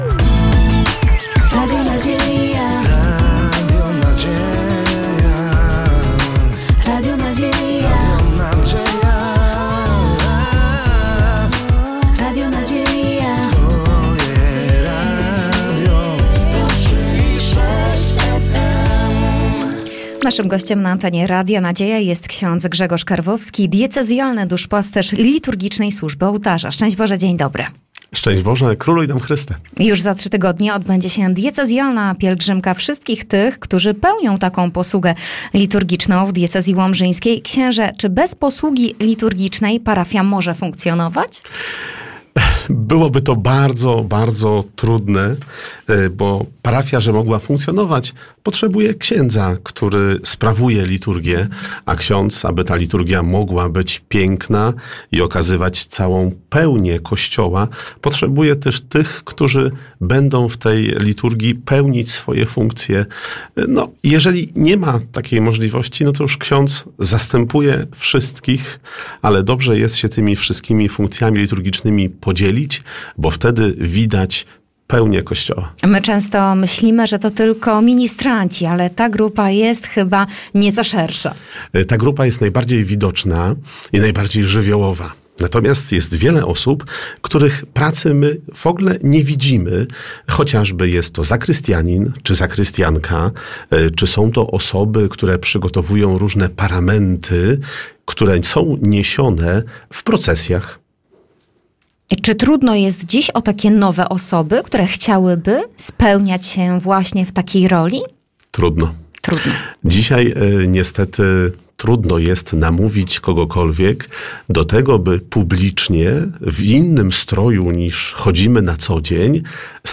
Rozmowa RN